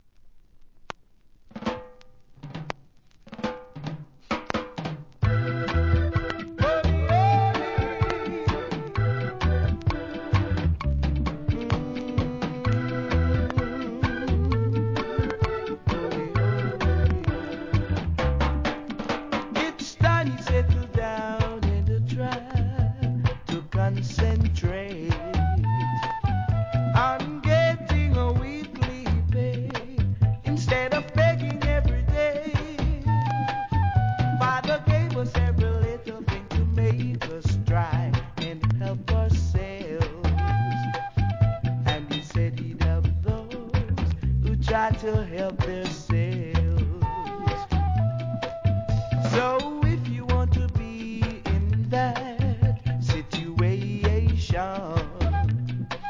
タイトル通りソウルフルでハートウォーミングな名曲が揃ってます!!